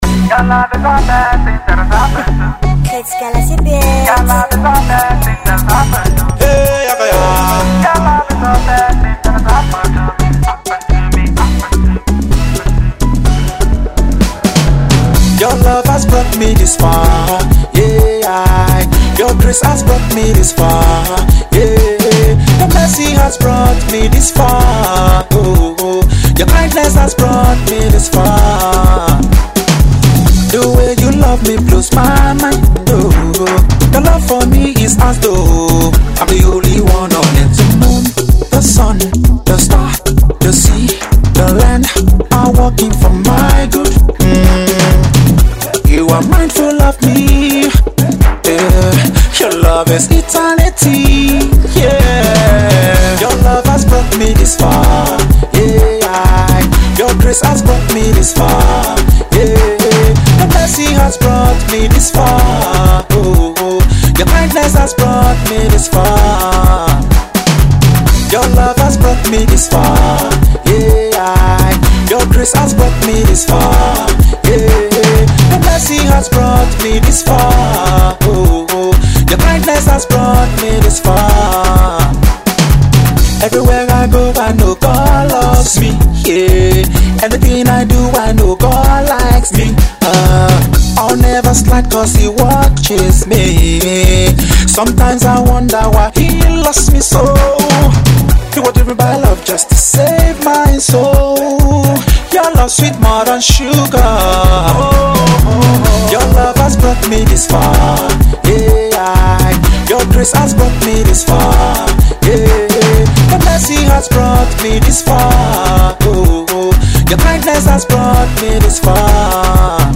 Gospel Minstrel